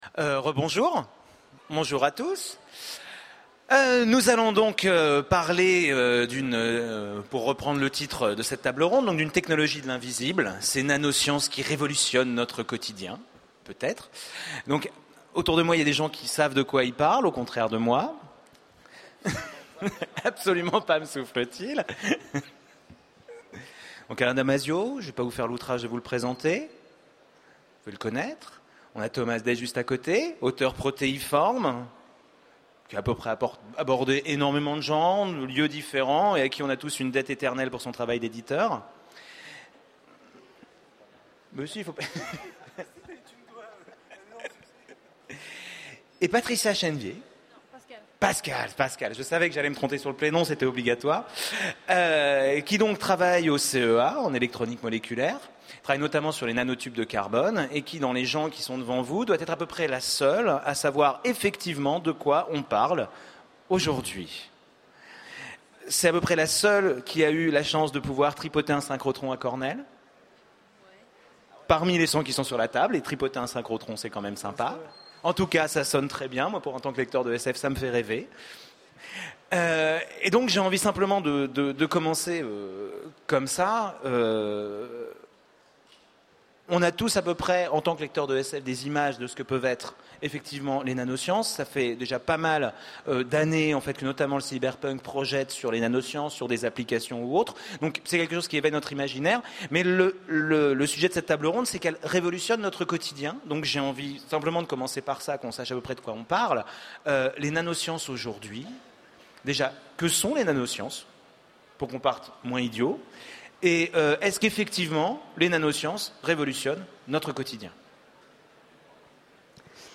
Utopiales 12 : Conférence Une technologie de l’invisible